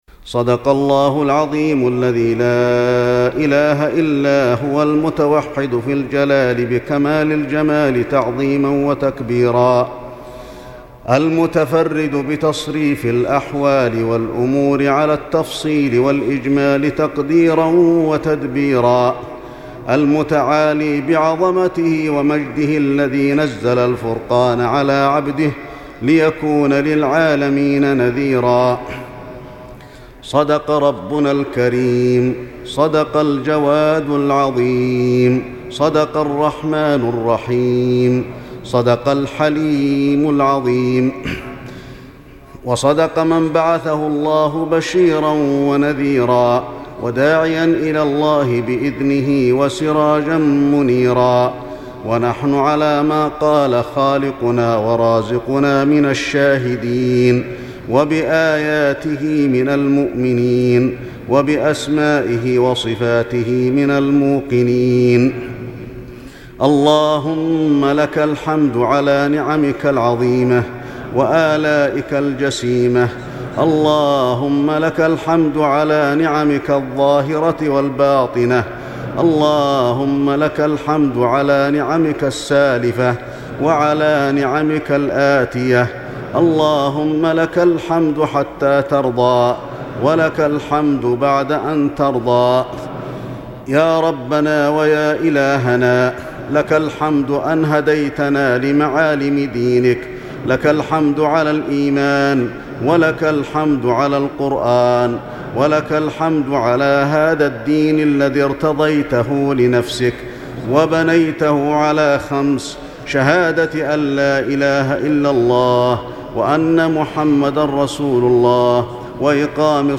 دعاء بصوت الشيخ علي الحذيفي - قسم أغســـــل قلــــبك 2